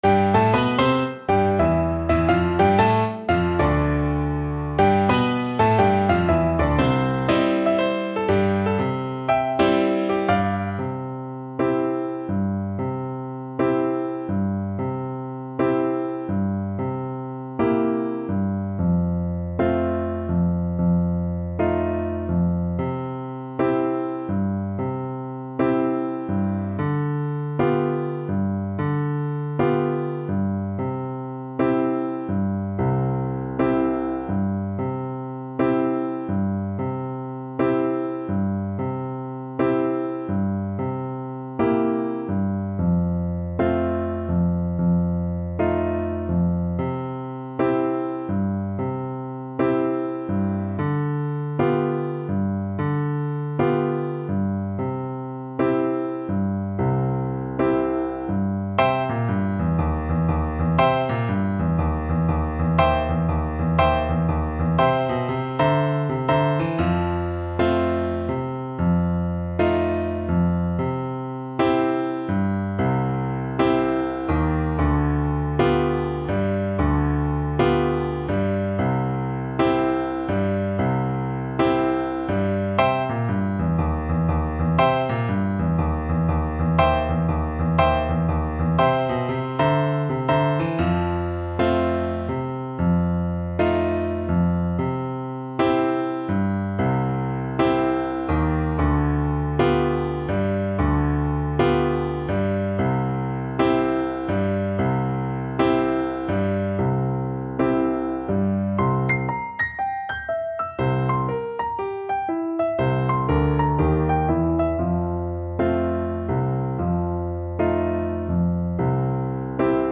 Moderato = 120
Jazz (View more Jazz French Horn Music)